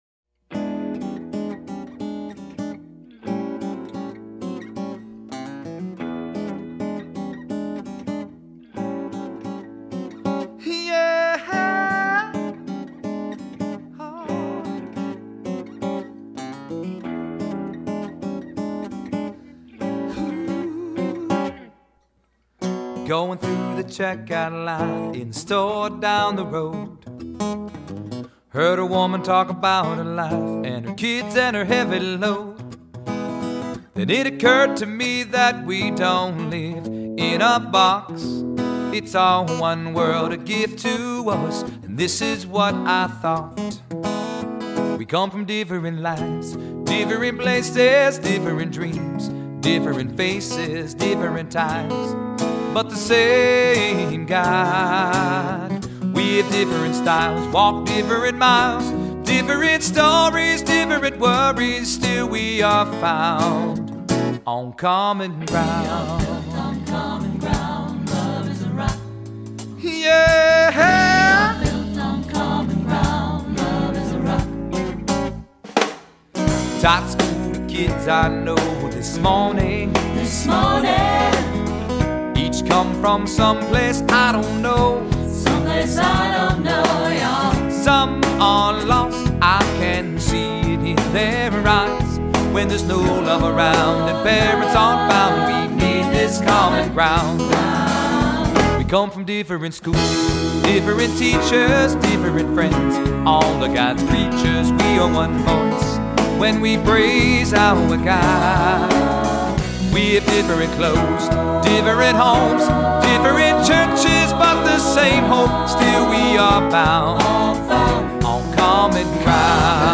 Voicing: "SATB", "Soloist"